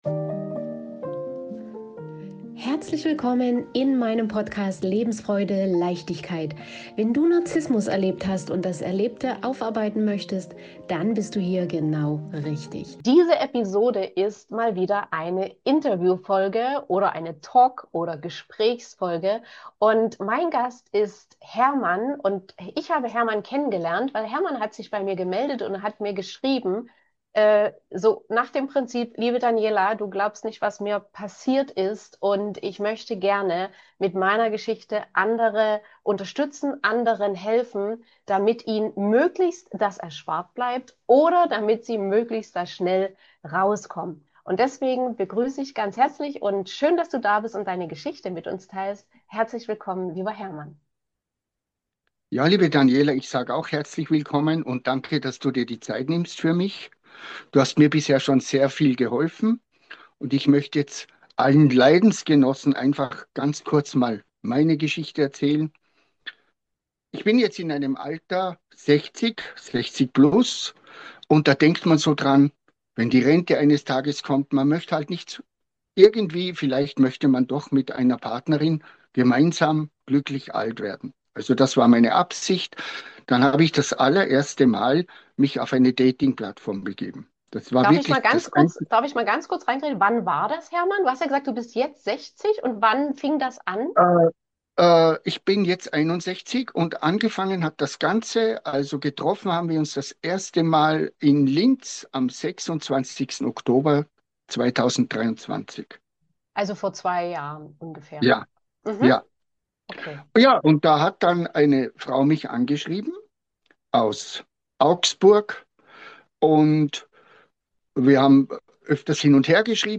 Meine Erfahrungen mit einer narzisstischen Frau - Interview